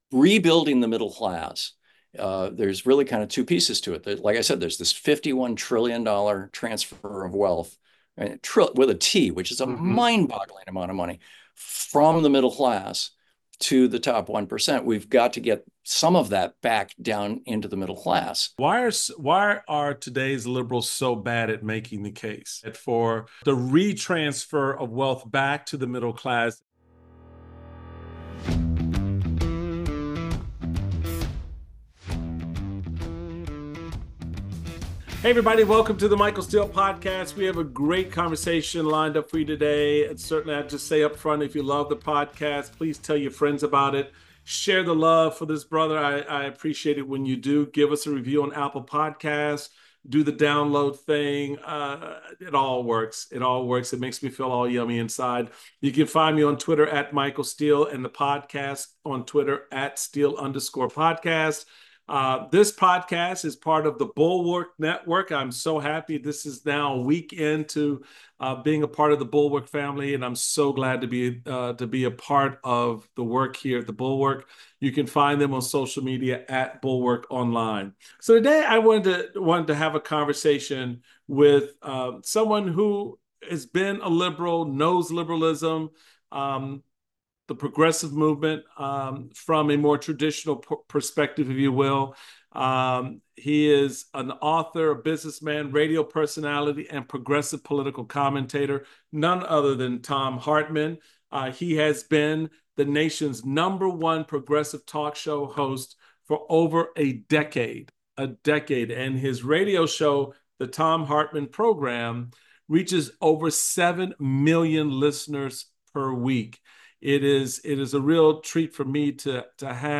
Michael Steele speaks with Progressive radio host, Thom Hartmann.